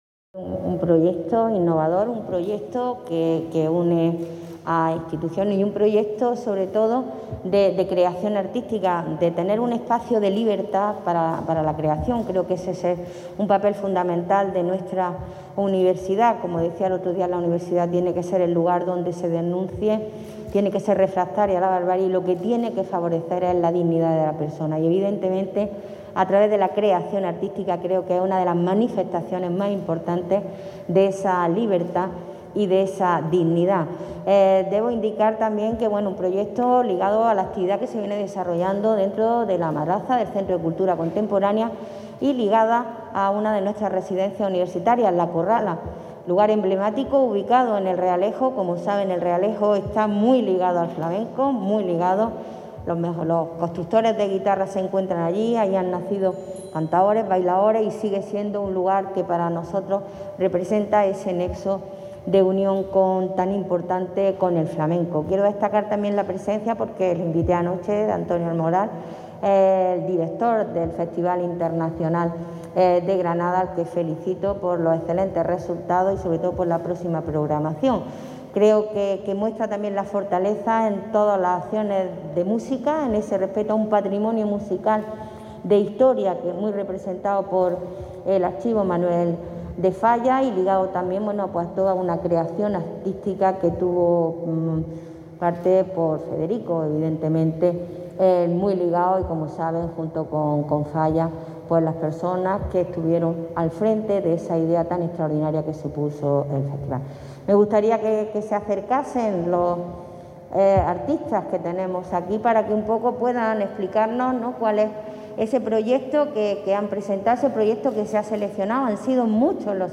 presentación-Los-Tientos-intervención-rectora
Intervención de Pilar Aranda sobre Los Tientos:
Rectora-UGR-sobre-los-tientos.mp3